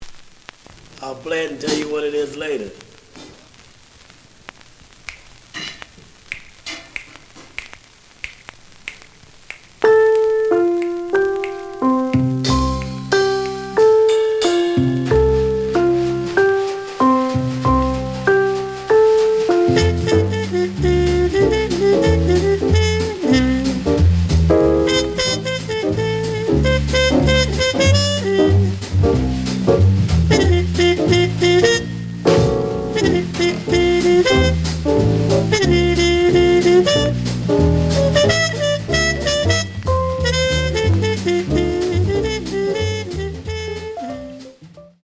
The audio sample is recorded from the actual item.
●Genre: Hard Bop